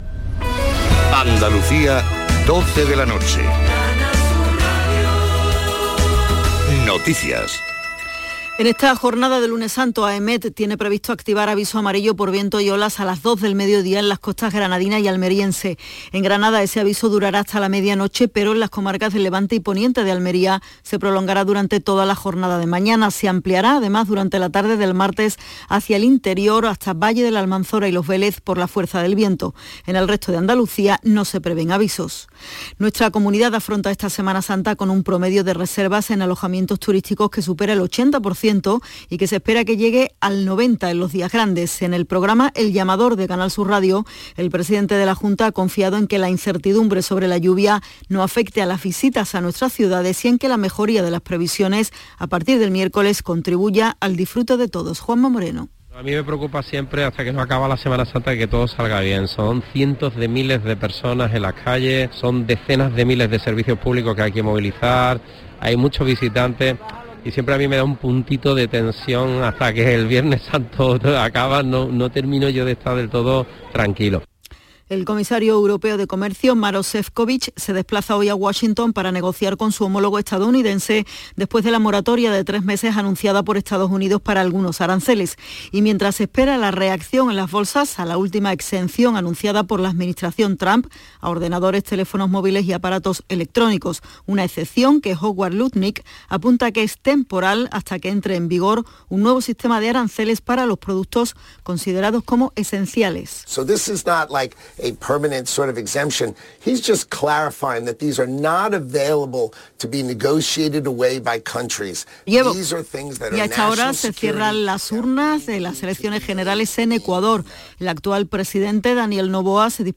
súbete a un recorrido fantástico por la risa, el humor más ingenioso y las entrevistas más originales. Canal Sur Radio | Domingos, justo después de la medianoche.